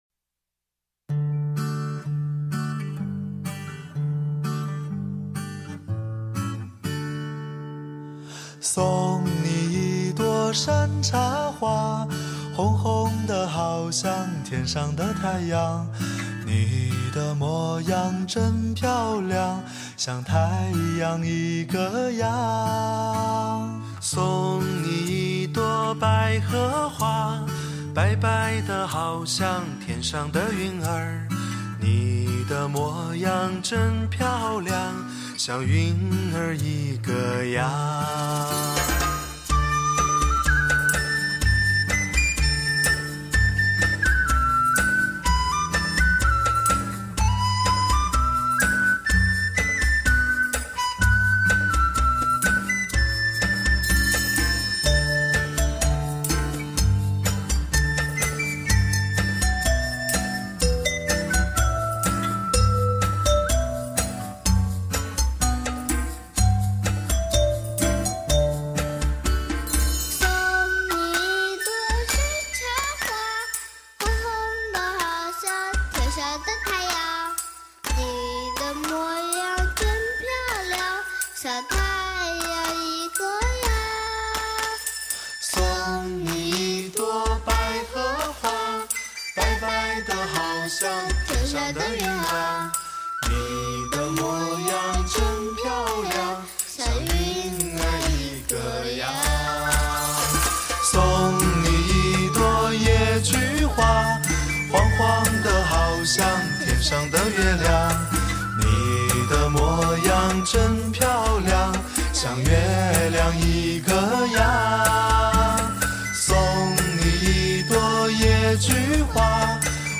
是属于民谣、童谣性质。